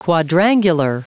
Prononciation du mot quadrangular en anglais (fichier audio)
Prononciation du mot : quadrangular